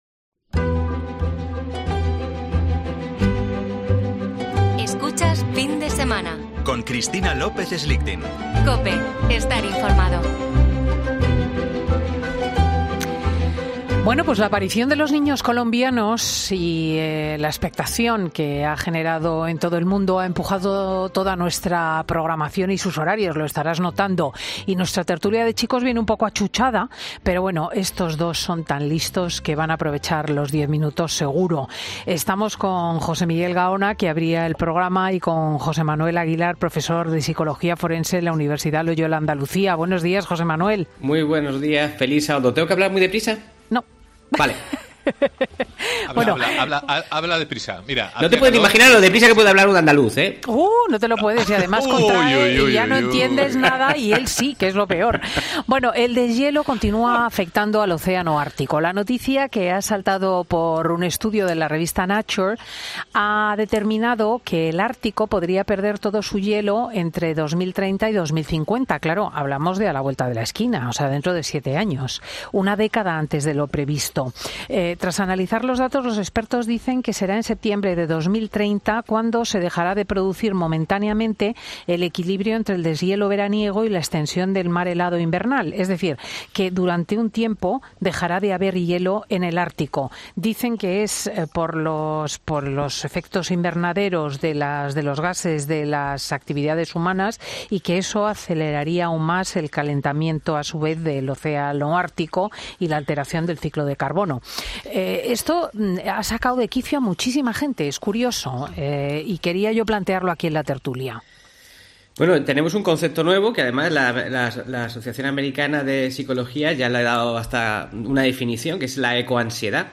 En la Tertulia hablamos de 'Ecoansiedad': "El temor crónico a sufrir un cataclismo ambiental".